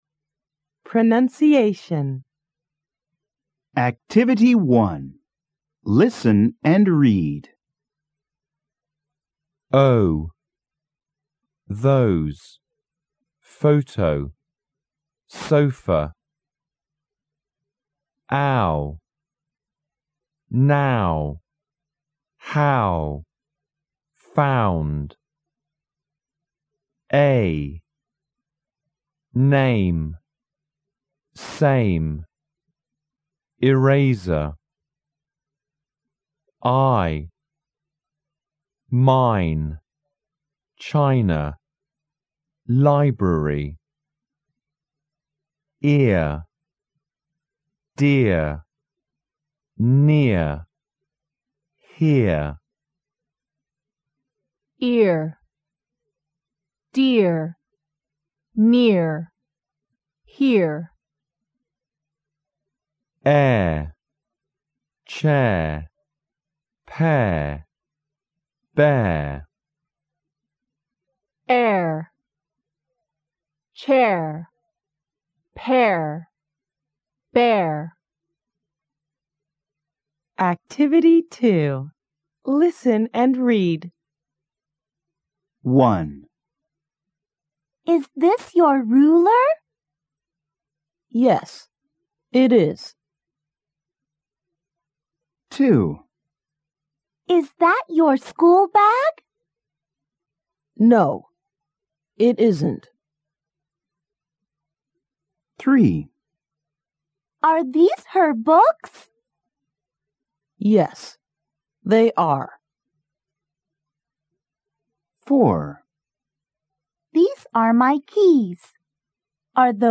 【素材】Unit 3 Is this your pencil 第1课时 pronunciation.mp3